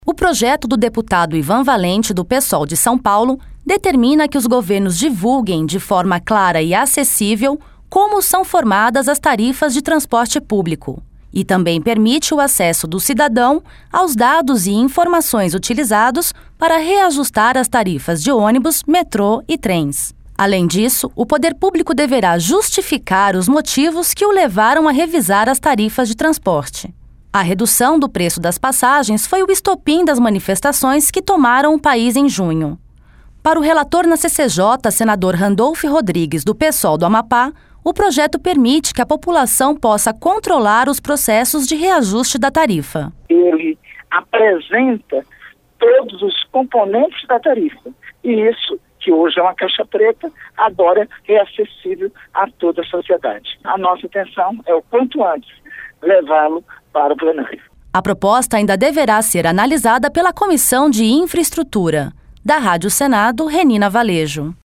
Para o relator na CCJ, senador Randolfe Rodrigues, do Psol do Amapá, o projeto permite que a população possa controlar os processos de reajuste da tarifa.